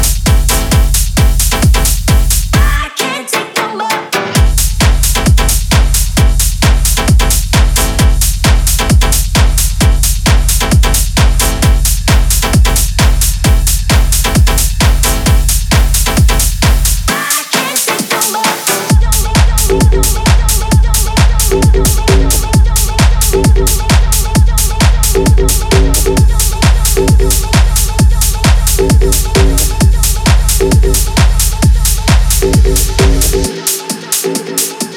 # Танцевальная